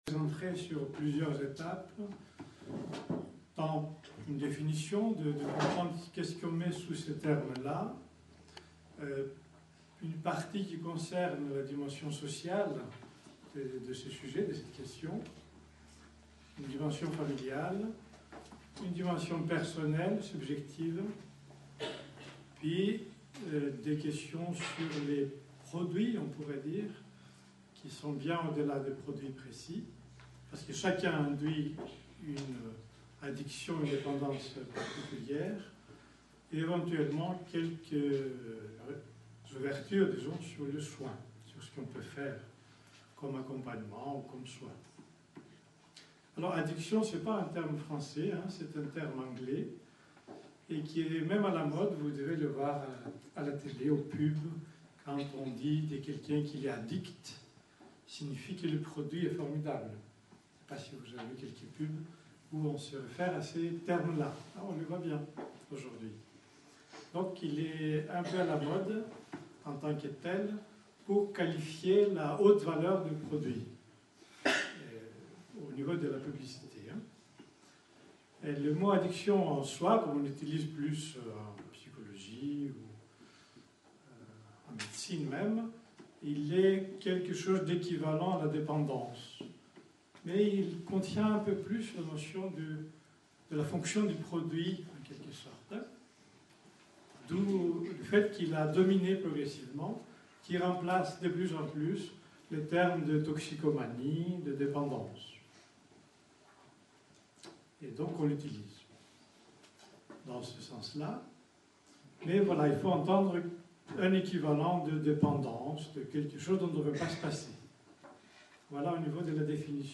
Une conférence de l'UTLS au Lycée Drogues et addictions